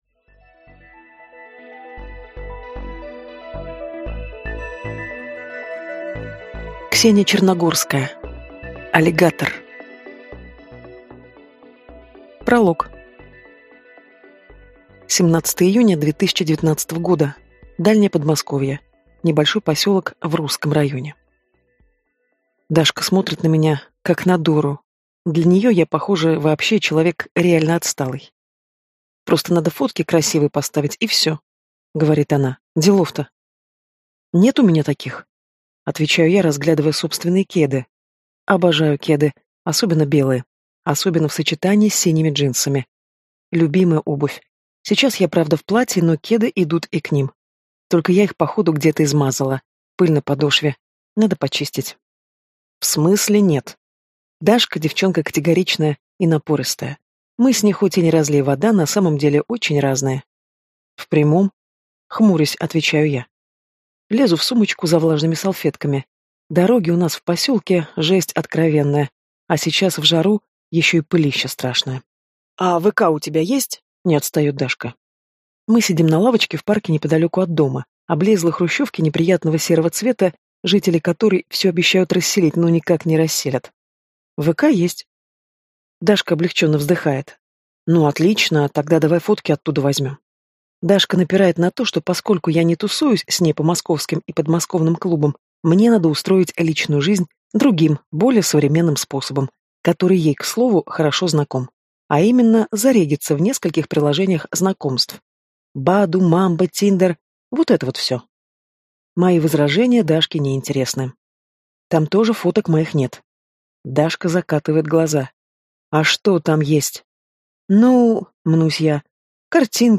Аудиокнига Аллигатор | Библиотека аудиокниг